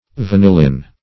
Vanillin \Va*nil"lin\, n. (Chem.)